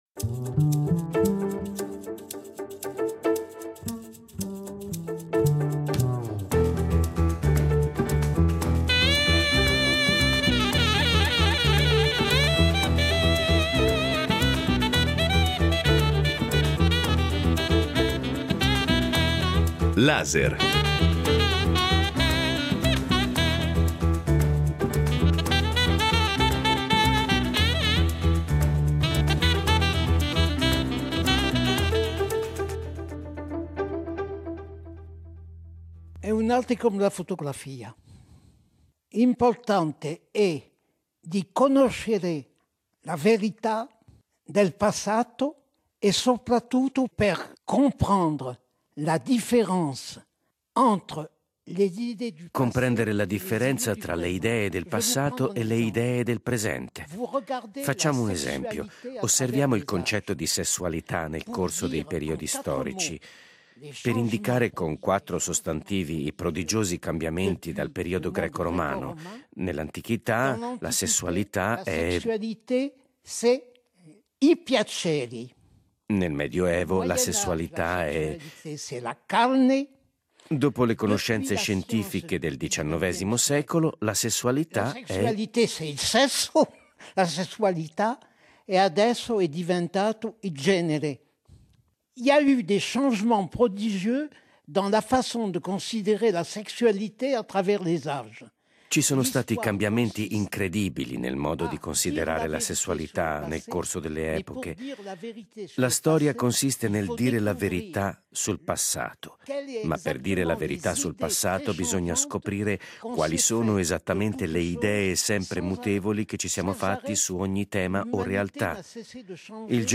LASER ha incontrato Paul Veyne nel 2017 a Bedoin, nel dipartimento del Vaucluse, nel sud della Francia, dove viveva da tempo e dove trovava ancora le energie per lavorare e scrivere.